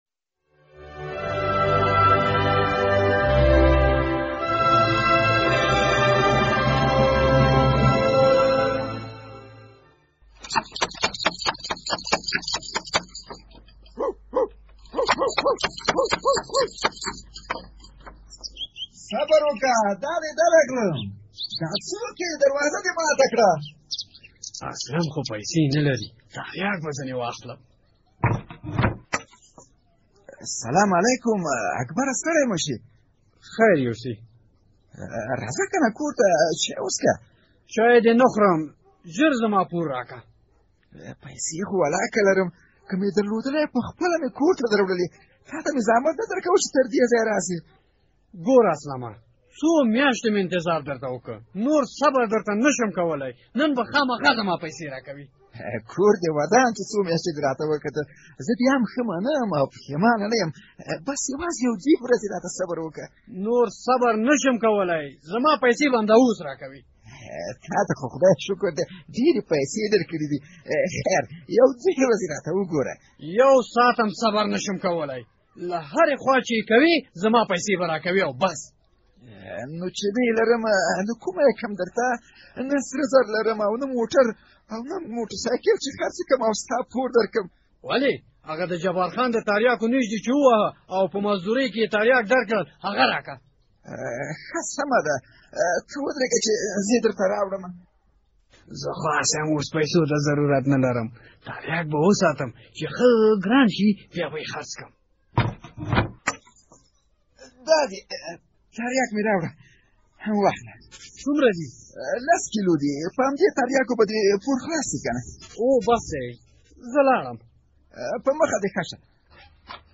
د زهرو کاروان پروګرام ډرامه